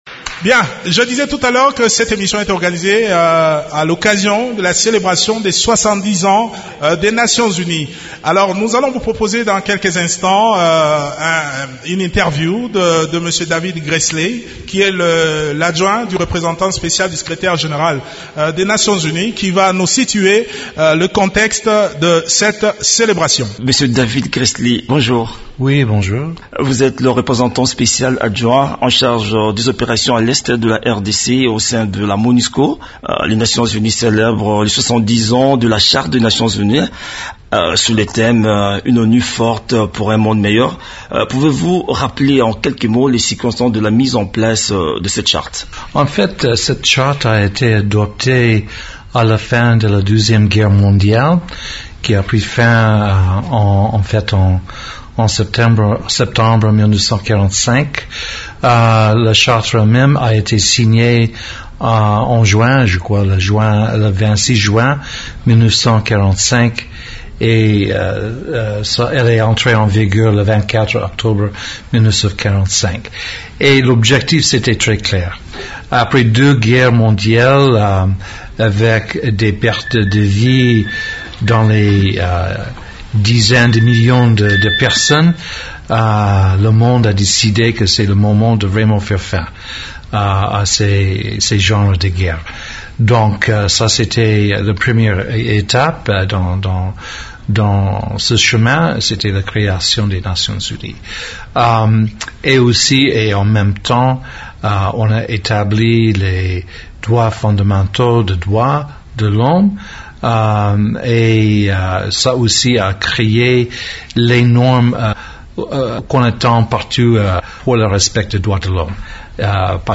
A l’occasion de la célébration de soixante-dix ans de l’Organisation des Nations unies (ONU), David Gressly représentant spécial adjoint chargé des opérations de la paix à l’Est de la RDC au sein de la Mission de l’Organisation des Nations unies pour la stabilisation en République Démocratique du Congo (Monusco) a accordé une interview à Okapi Service au cours de laquelle il retrace le contexte de la création de la charte des Nations unies ainsi que le bilan des activités de l’ONU en RDC.